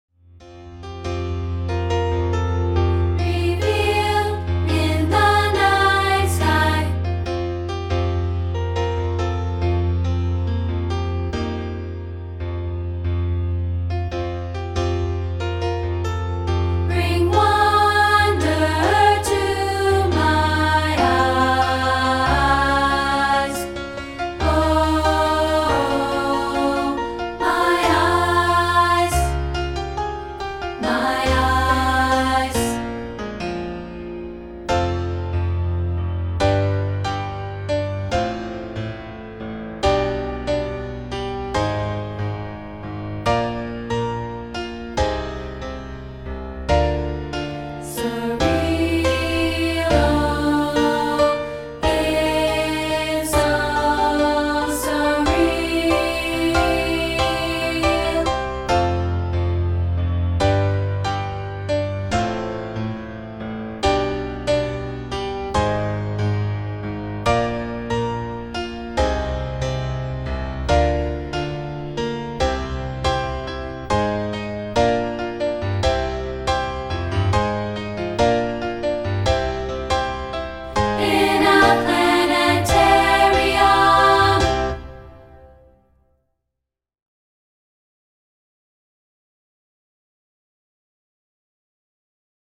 including this rehearsal track of part 2, isolated.